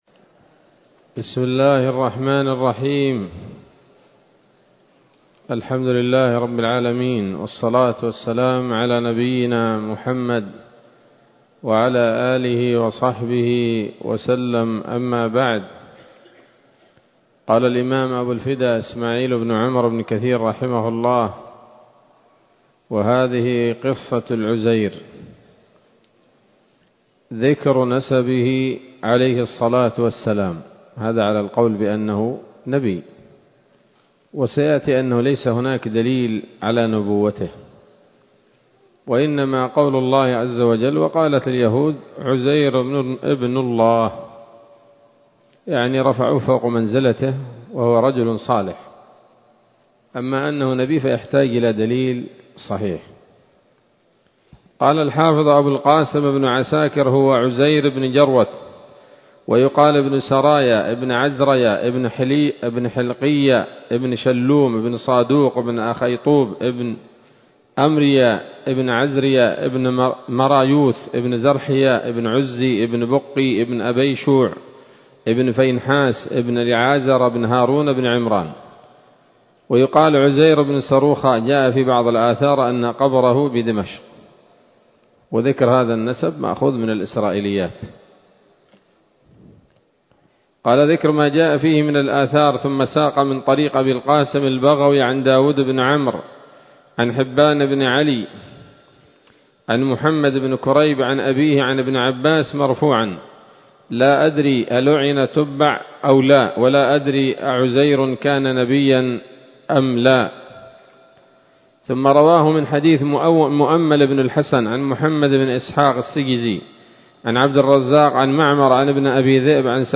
‌‌الدرس الحادي والثلاثون بعد المائة من قصص الأنبياء لابن كثير رحمه الله تعالى